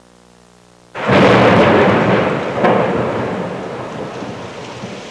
Thunder Sound Effect
Download a high-quality thunder sound effect.
thunder-1.mp3